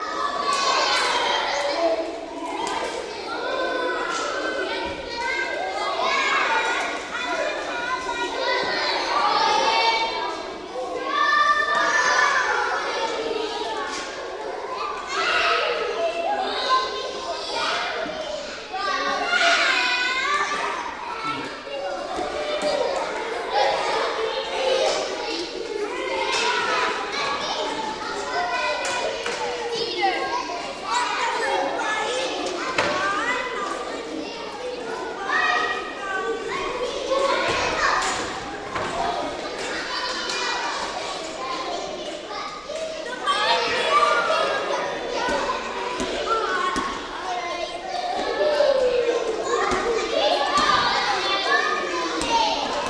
На этой странице собраны звуки, характерные для детского лагеря: смех ребят, шум игр на свежем воздухе, вечерние посиделки с гитарой и другие атмосферные моменты.
Шум детей в лагерной столовой